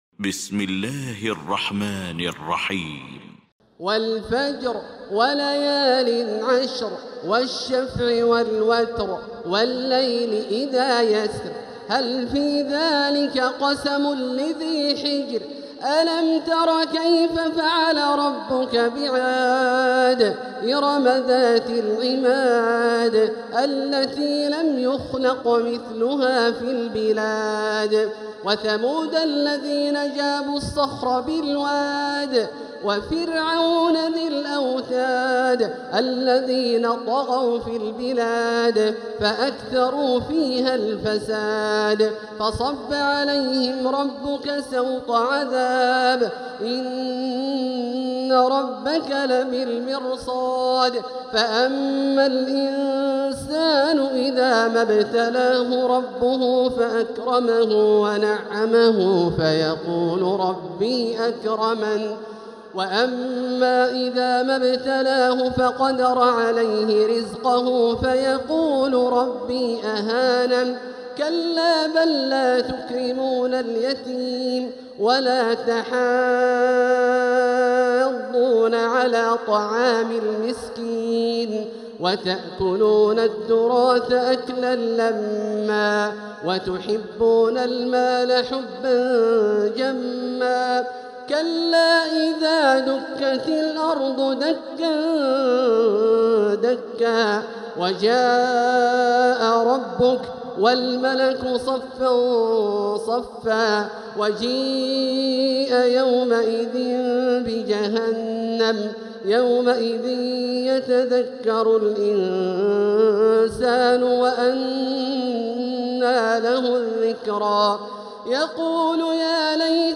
المكان: المسجد الحرام الشيخ: فضيلة الشيخ عبدالله الجهني فضيلة الشيخ عبدالله الجهني الفجر